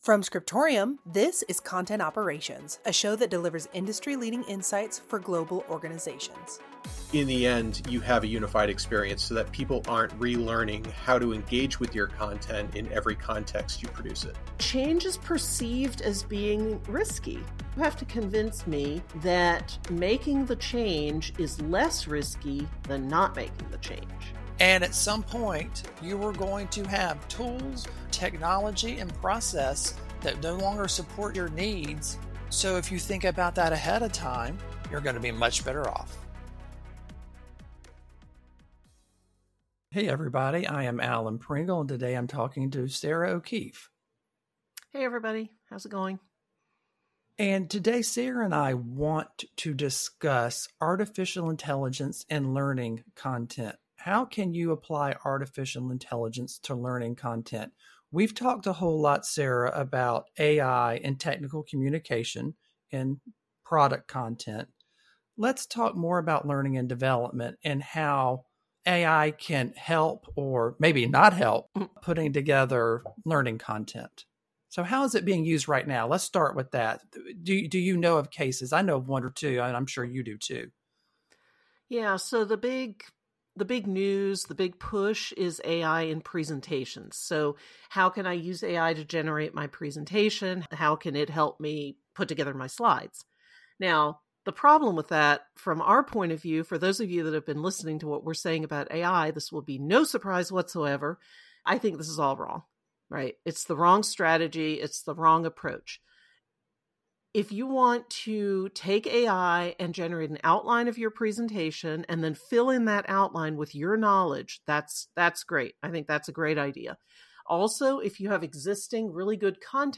Introduction with ambient background music